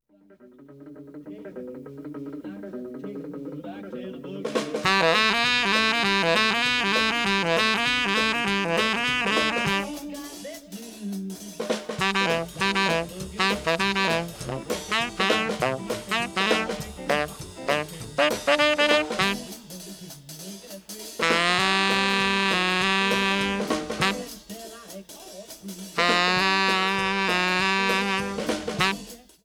SAX OPTRON 3A.wav